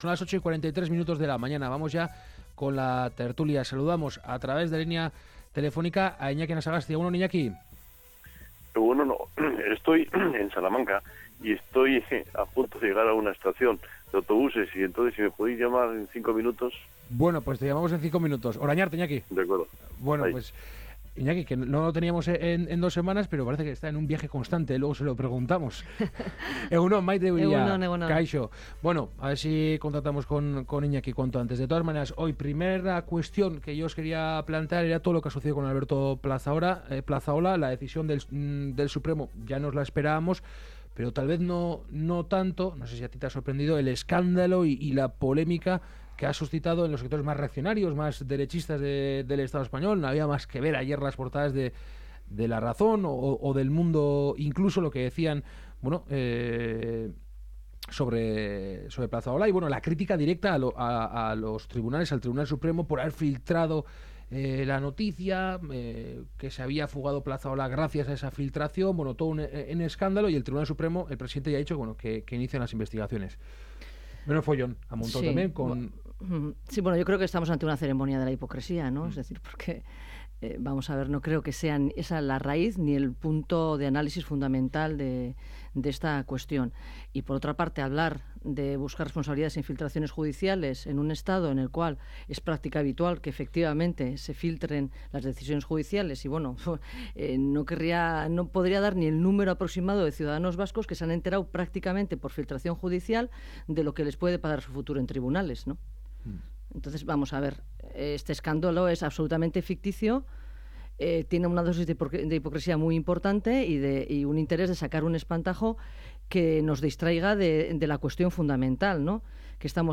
Charlamos y debatimos sobre algunas de las noticias más comentadas de la semana con nuestros colaboradores habituales.